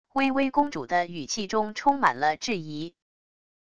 薇薇公主的语气中充满了质疑wav音频生成系统WAV Audio Player